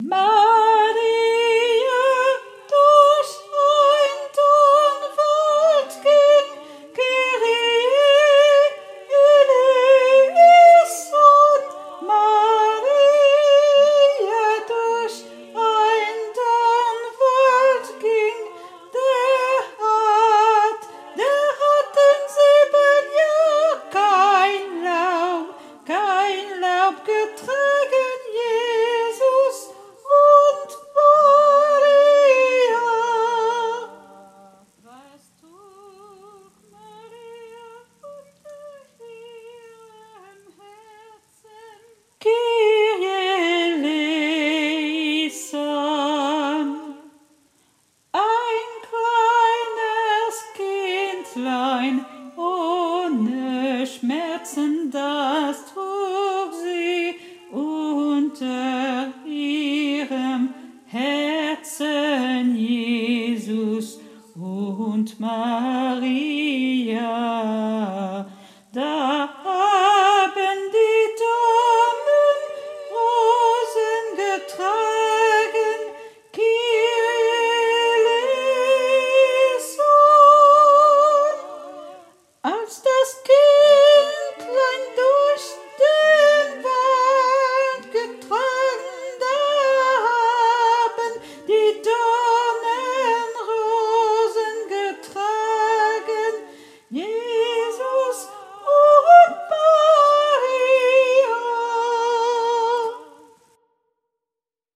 MP3 versions chantées
Tenor